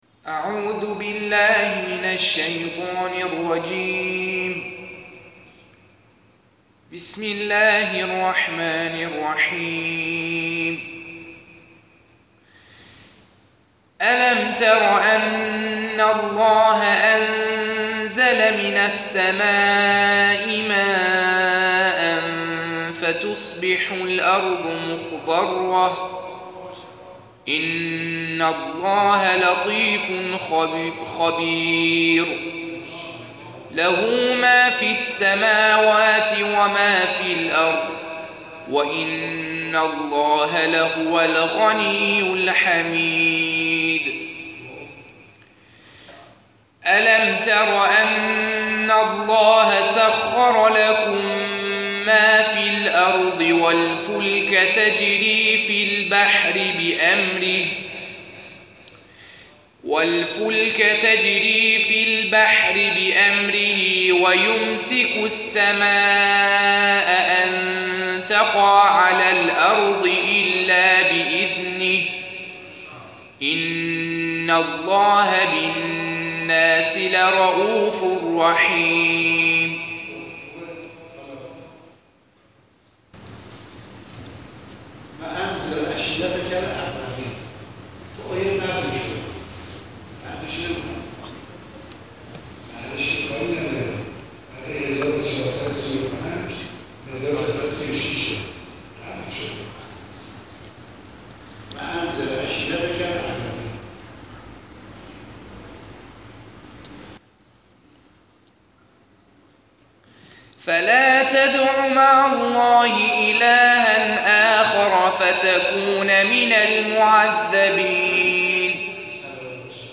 را به جامعه قرآنی تحویل داده است، 38 سال است که با تمام مشکلات و مسائل موجود در مسجد، ساخته است و هر هفته بعد از نماز مغرب و عشاء برگزار می‌شود.
در ابتدا، اعضای جلسه به تلاوت قرآن پرداختند